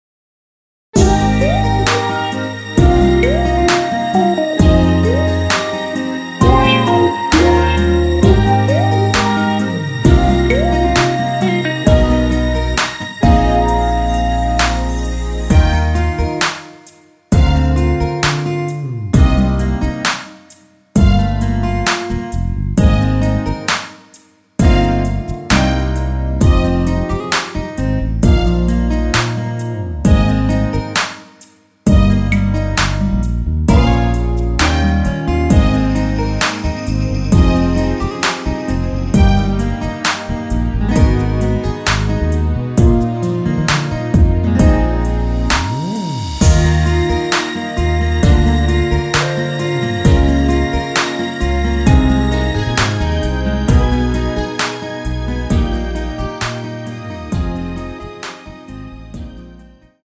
전주후 바쁜 하루 중에도…으로 진행이 됩니다.
Eb
앞부분30초, 뒷부분30초씩 편집해서 올려 드리고 있습니다.
중간에 음이 끈어지고 다시 나오는 이유는